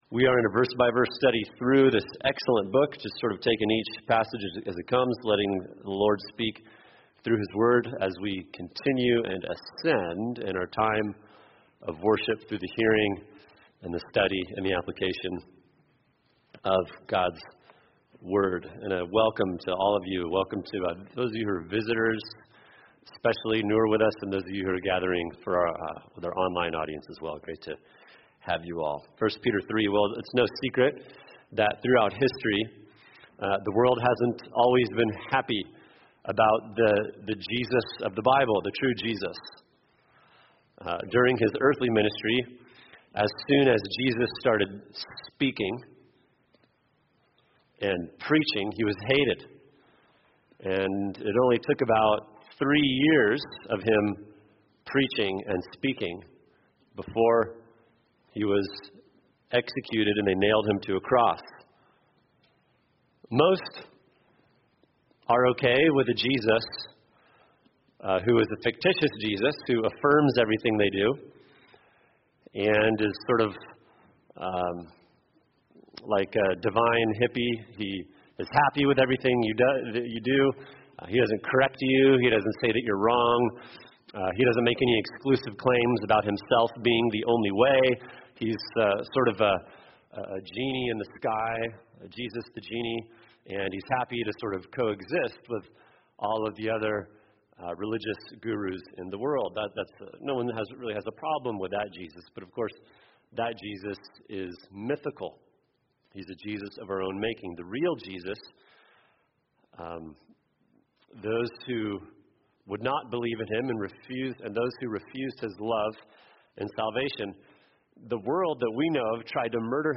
[sermon] 1 Peter 3:13-17 Strength to Face Hostility – Part 2 | Cornerstone Church - Jackson Hole